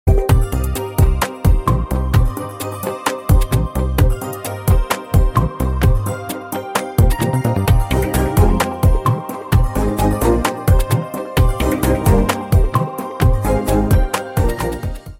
موسيقى هادئة